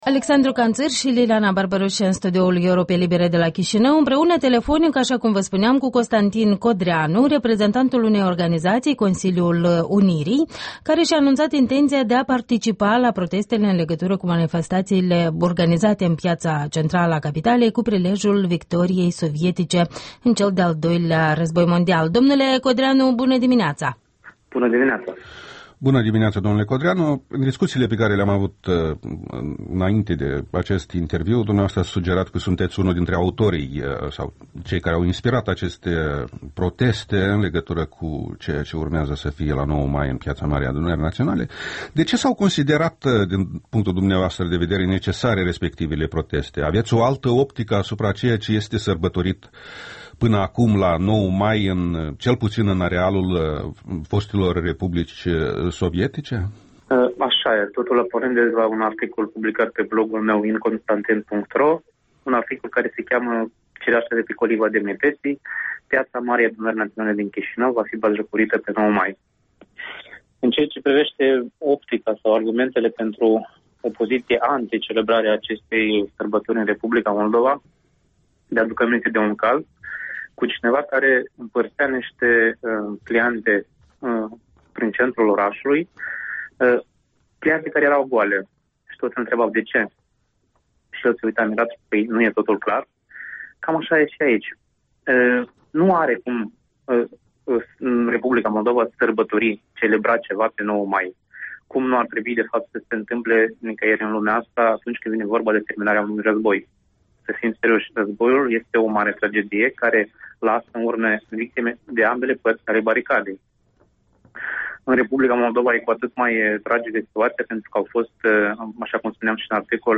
Interviul dimineții: cu Constantin Codreanu (Consiliul Unirii)